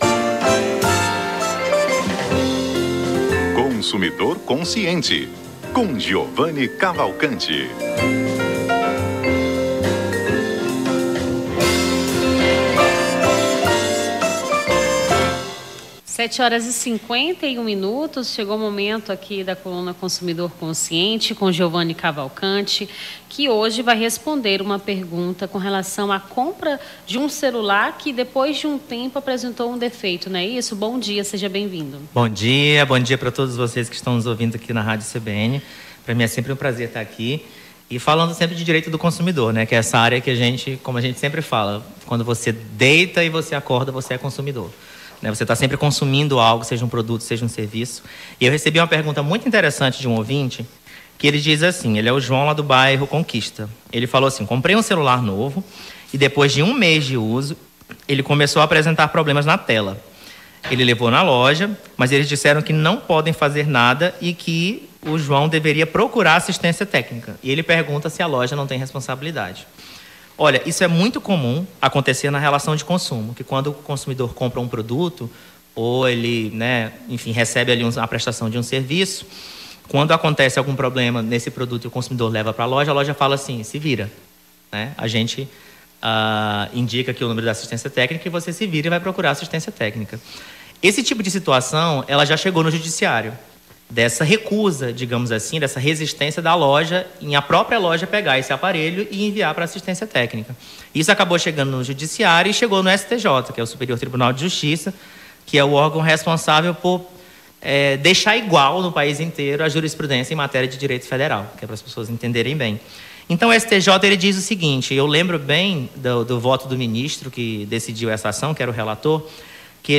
Consumidor Consciente: advogado tira-dúvidas sobre o direito do consumidor